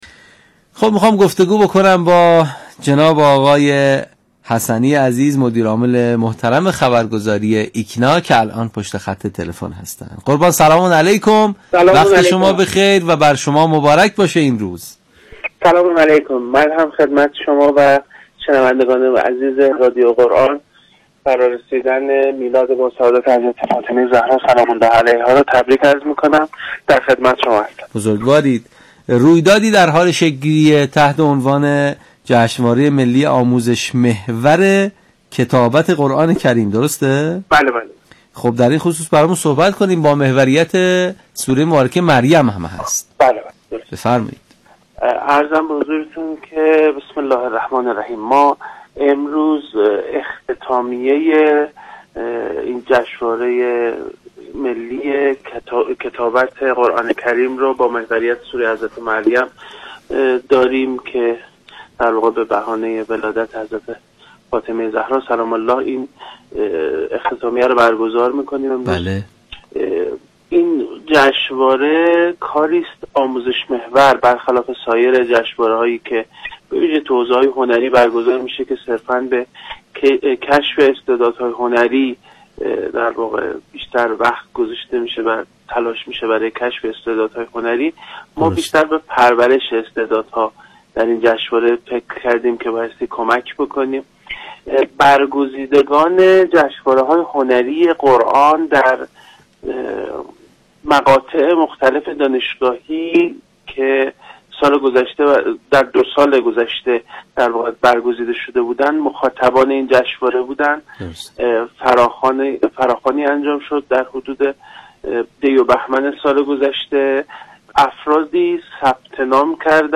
مجله صبحگاهی «تسنیم» به صورت زنده از ساعت ۷ تا ۸ صبح از رادیو قرآن(موج اف ام، ردیف ۱۰۰ مگاهرتز) پخش می‌شود.